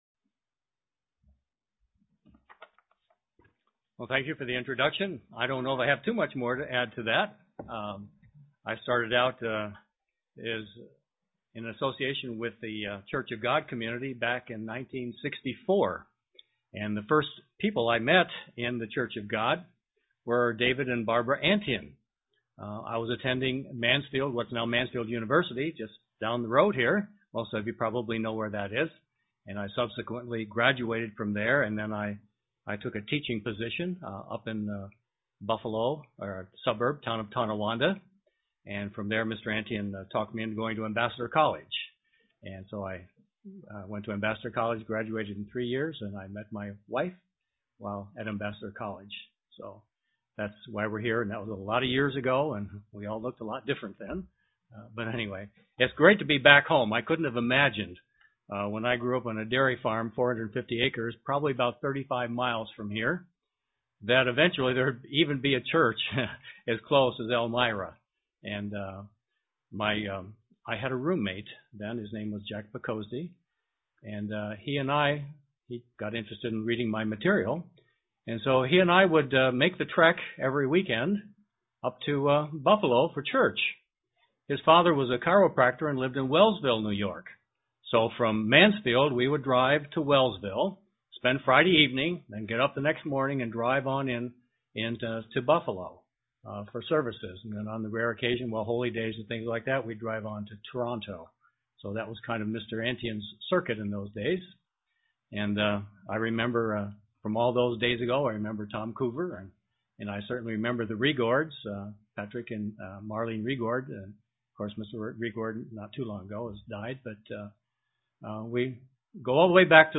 Print What are spiritual orphans UCG Sermon Studying the bible?